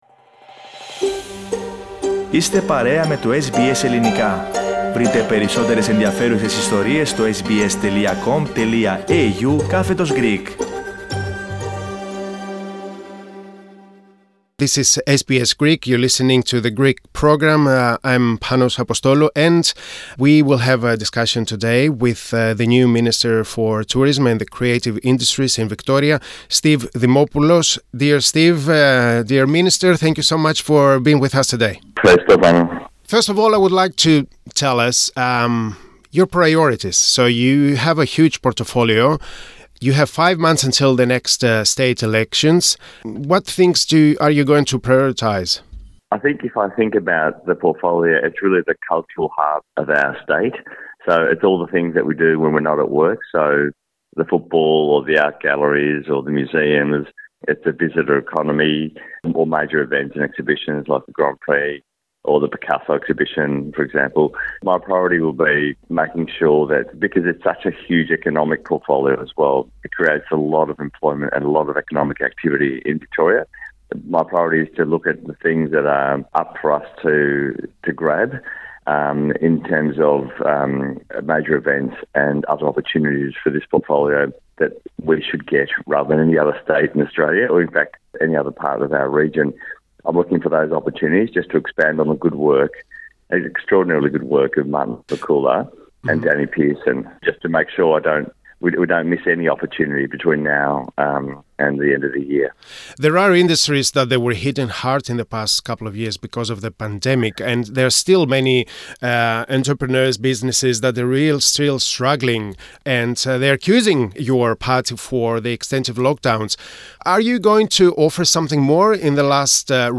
Ανέλαβε τα καθήκοντά του ο νέος υπουργός Τουρισμού, Τεχνών και Θεαμάτων, ομογενής Σταύρος Δημόπουλος, ο οποίος γίνεται ο πρώτος Ελληνικής καταγωγής ανοιχτά ομοφυλόφιλος υπουργός σε κυβέρνηση της Βικτώριας. Μίλησε στο Πρόγραμμά μας, μεταξύ άλλων, για τα νέα του υπουργικά καθήκοντα.